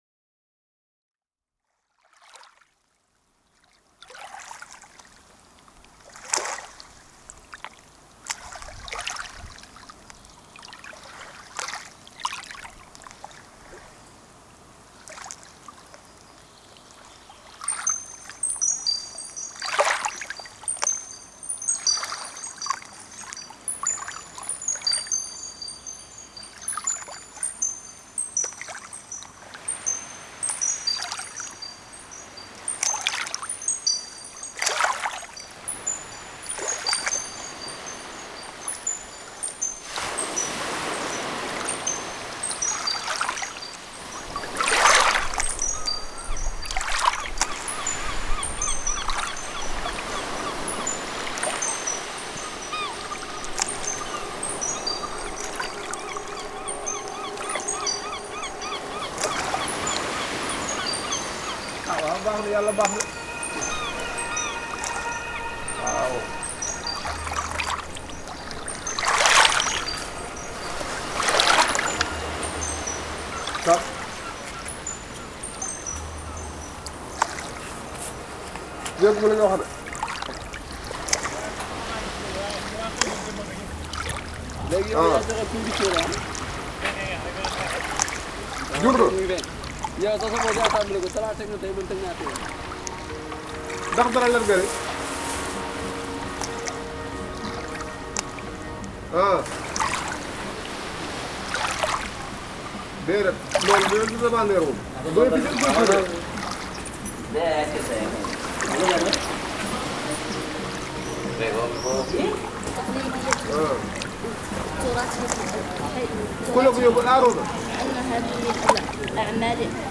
Il paesaggio sonoro “One breath” è il risultato dell’elaborazione di materiali audio legati a Cosmomed e provenienti dai progetti presentati all’interno della mostra Tracce. L’ensamble sonoro tende a fondere insieme testimonianze di vita comune in aree geografiche culturalmente distanti. Attraverso un mare che “tutto bagna” si è immaginato un viaggio che porti insieme, in unico respiro, le espressioni di popoli che possono convivere.